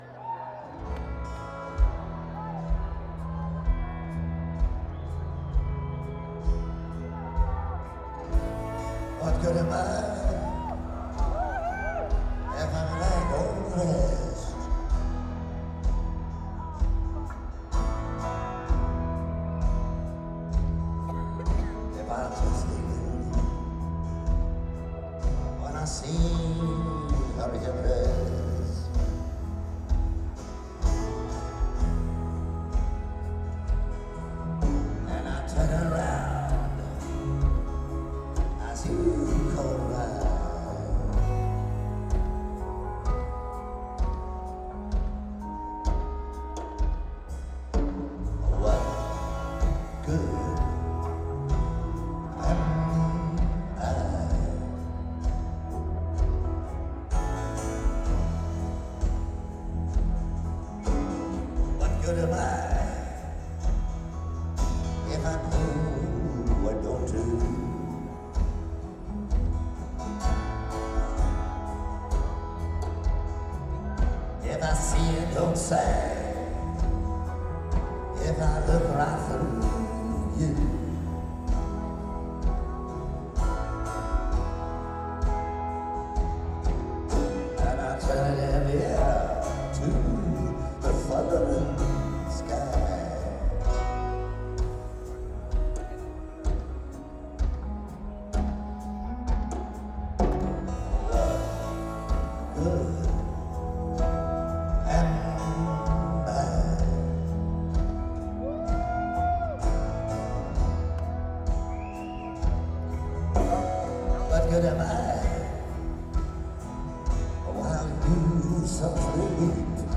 Live at the Marquee - Cork, Ireland